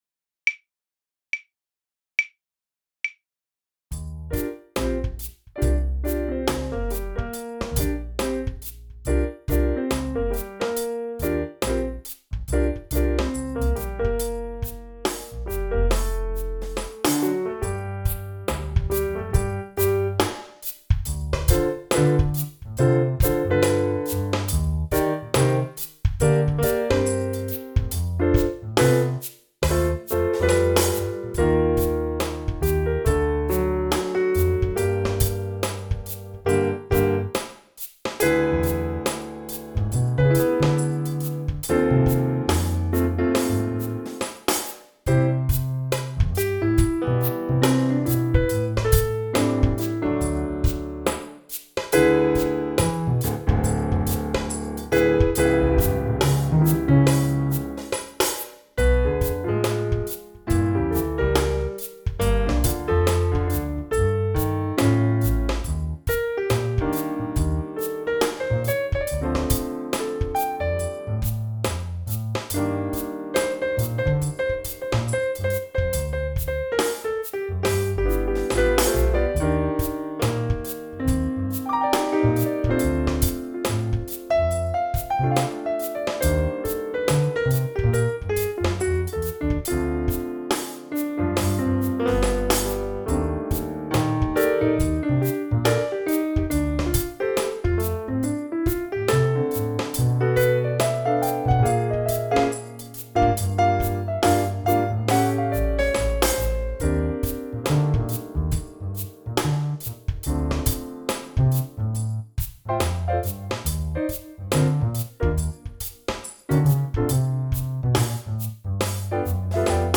It will be a four-camera live production shoot.